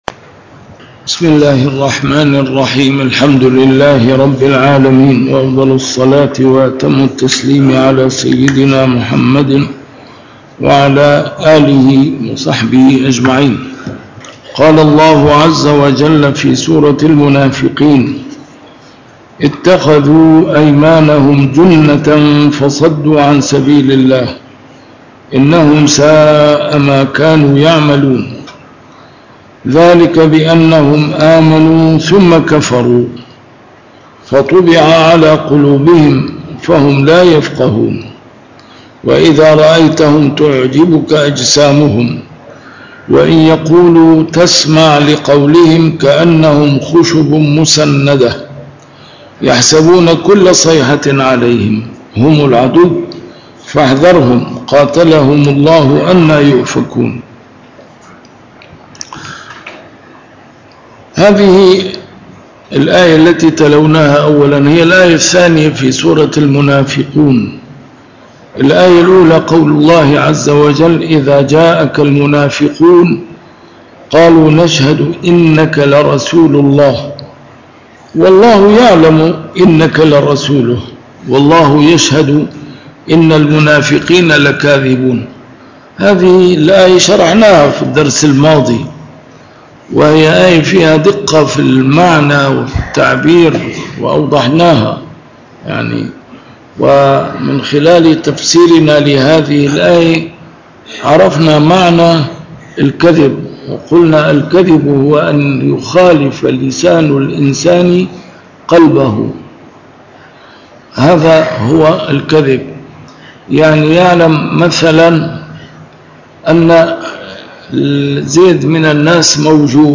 A MARTYR SCHOLAR: IMAM MUHAMMAD SAEED RAMADAN AL-BOUTI - الدروس العلمية - تفسير القرآن الكريم - تسجيل قديم - الدرس 794: المنافقون 02-03